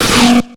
Cri d'Ymphect dans Pokémon X et Y.